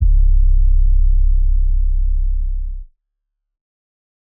808s
nightcrawler sub.wav